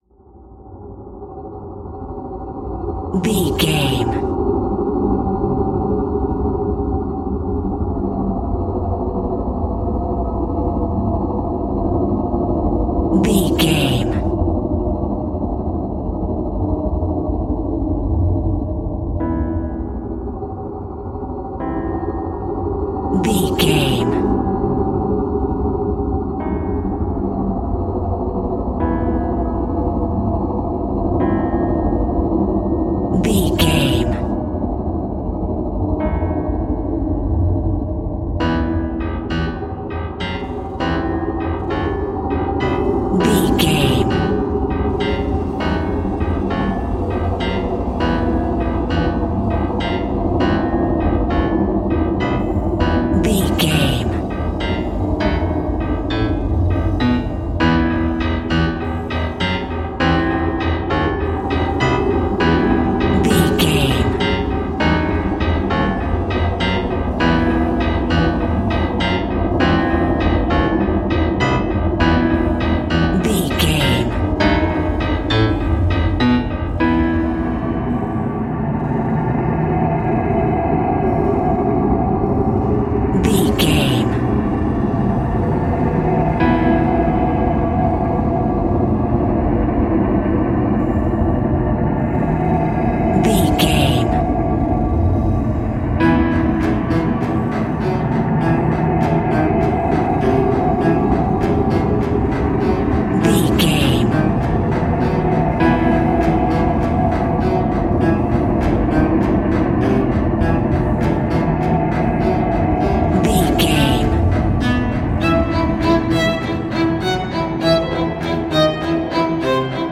In-crescendo
Thriller
Aeolian/Minor
tension
ominous
suspense
haunting
eerie
strings
synth
ambience
pads